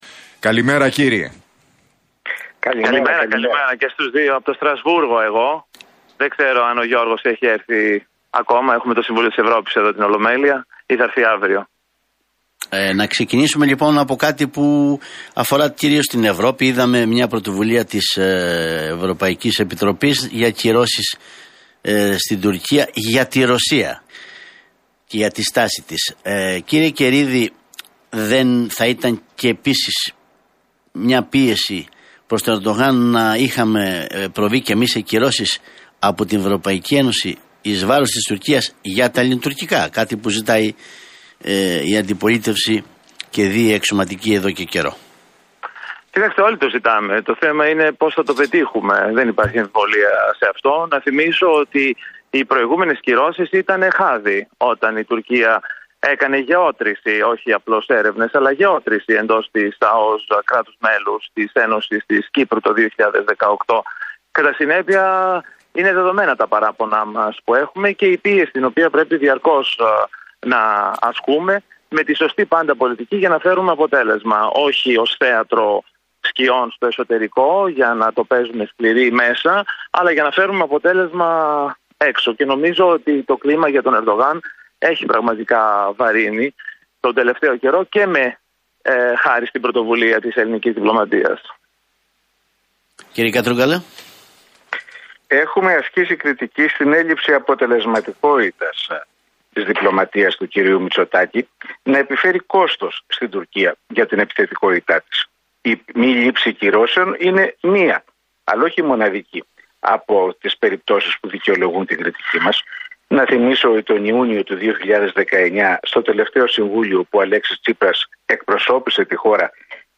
Τουρκικές προκλήσεις: Debate Καιρίδη – Κατρούγκαλου στον Realfm 97,8
Τα ξίφη τους διασταύρωσαν στον αέρα του Realfm 97,8 ο βουλευτής της ΝΔ Δημήτρης Καιρίδης και ο τομεάρχης Εξωτερικών του ΣΥΡΙΖΑ, Γιώργος Κατρούγκαλος.